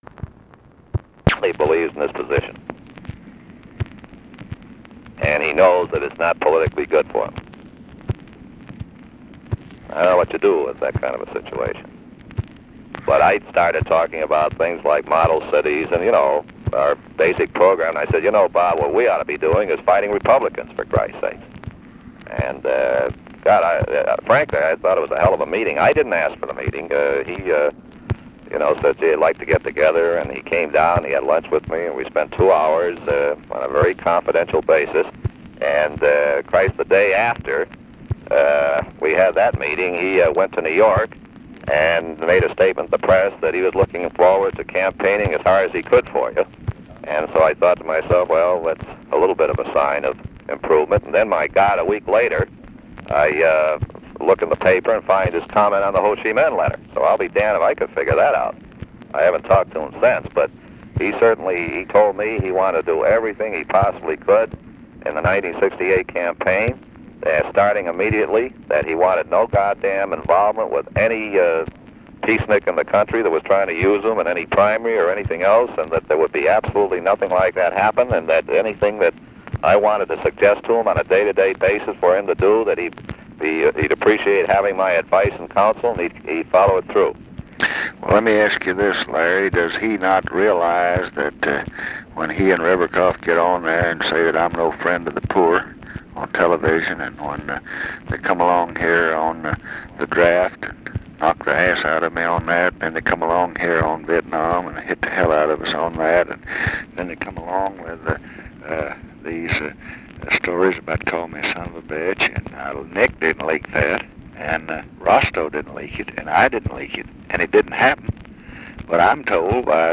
Conversation with LARRY O'BRIEN, March 30, 1967
Secret White House Tapes